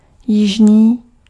Ääntäminen
US : IPA : [ˈsʌð.ɚn]